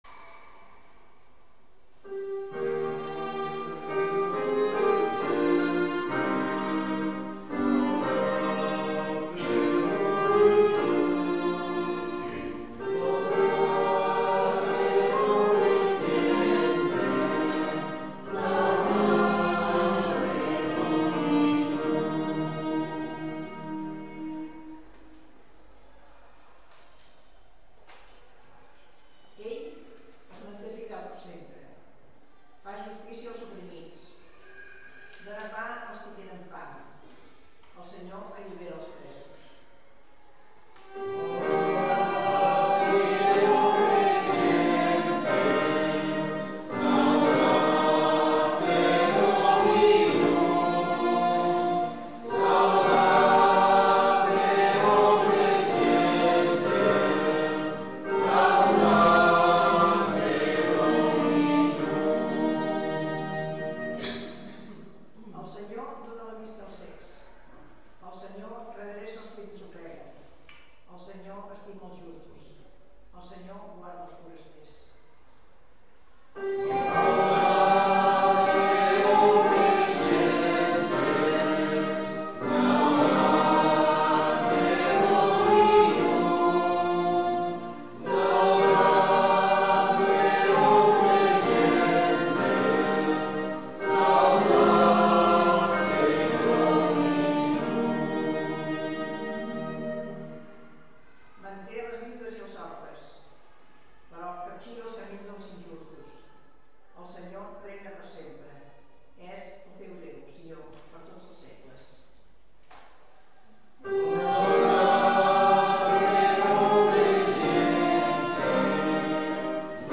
Pregària de Taizé
Pregària de Taizé Parròquia de Maria Auxiliadora - Diumenge 29 de setembre de 2013 Vàrem cantar...